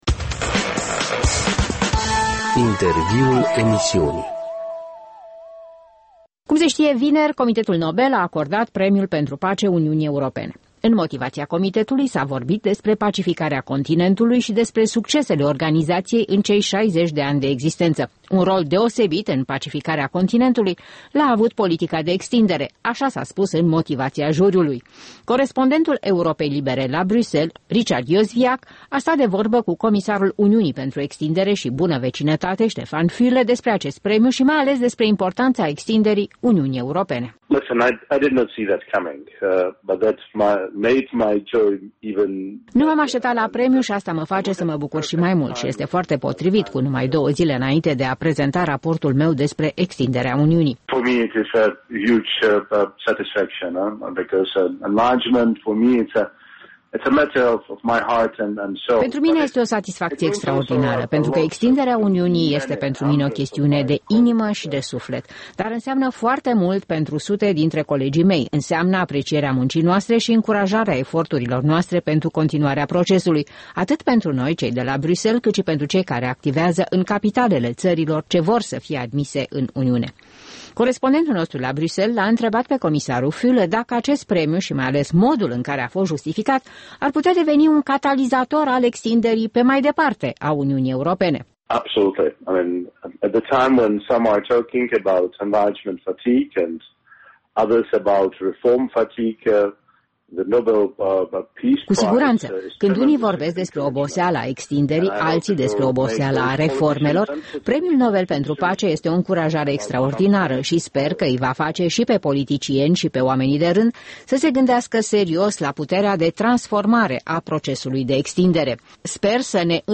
Interviul acordat de Comisarul UE, Ștefan Fuele, Europei Libere la Bruxelles